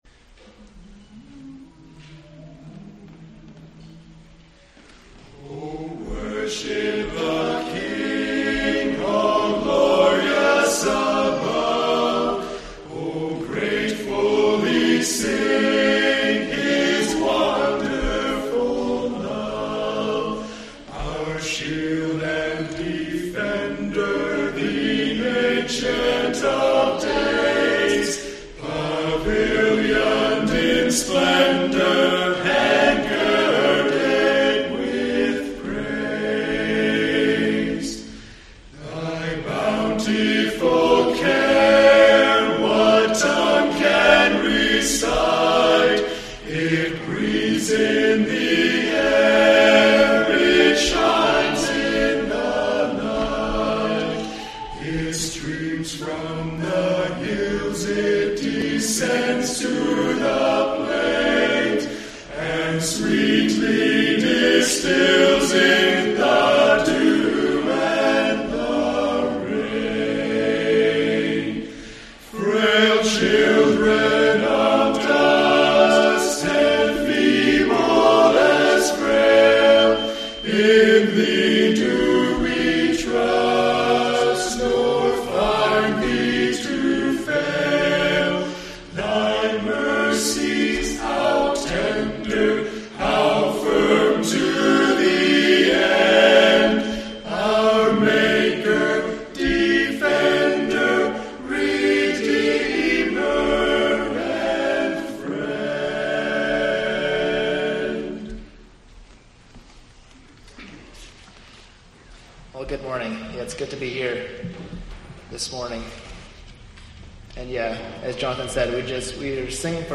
Mens Singing
Mens Singing Group.mp3